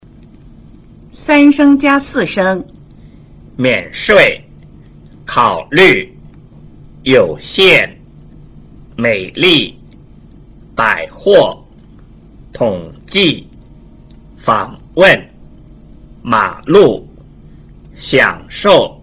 在語流中,第三聲詞往往讀成半三聲,也就是只讀音節聲調的降調部份。